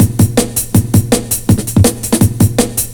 JUNGLE4-R.wav